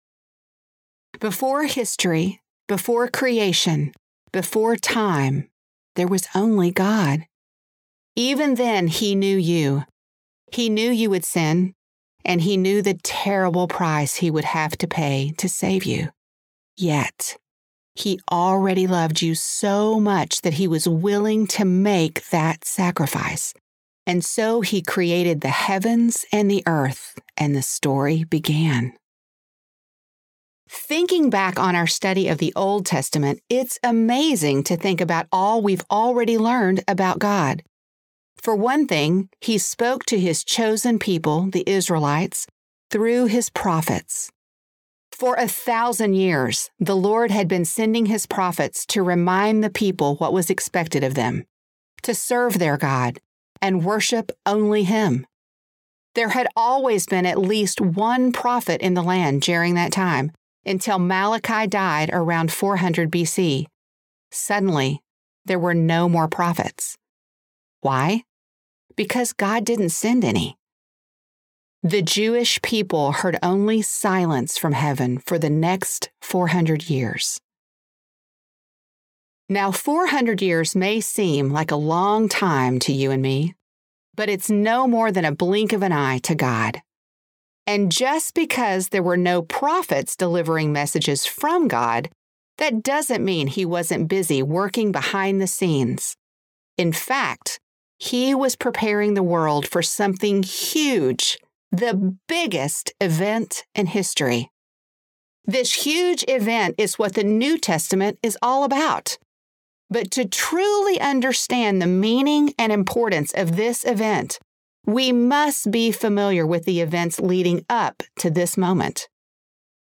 Click the button below to hear a sample of this audiobook.